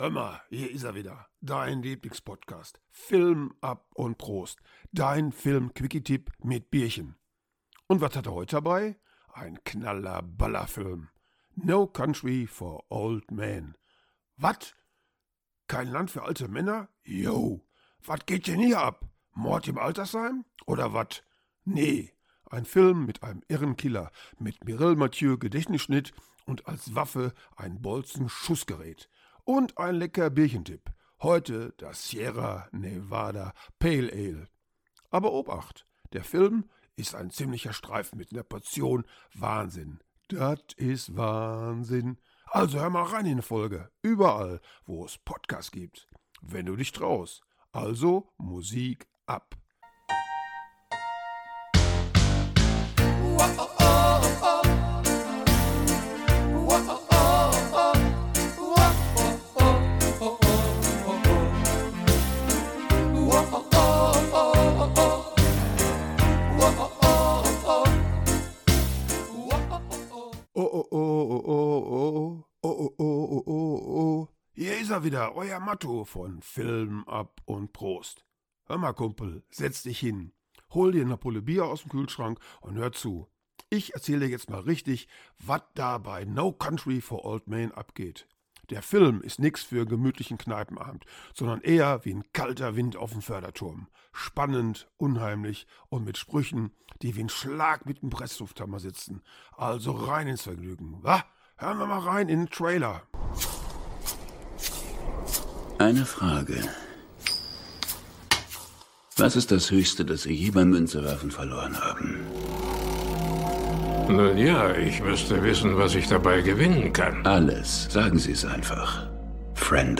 Der Filmquickie - Tipp mit Ruhrpottcharme und lecker Bierchen